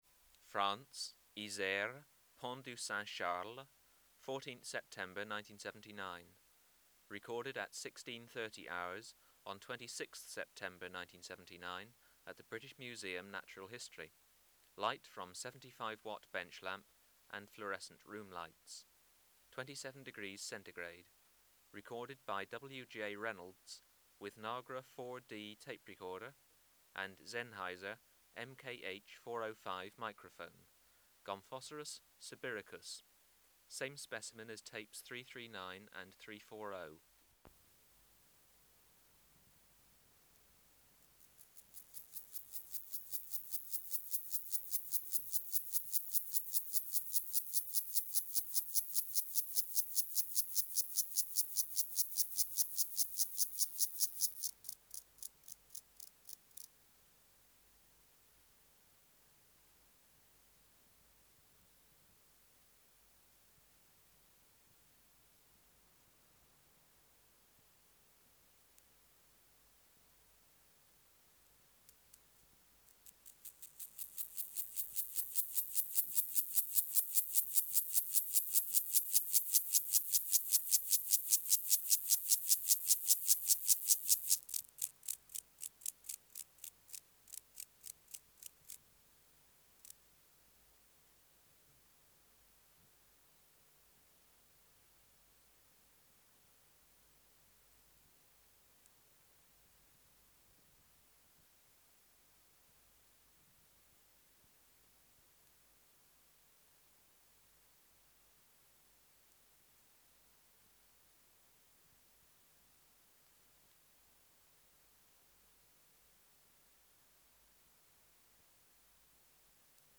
391:9 Gomphocerus sibiricus(338) | BioAcoustica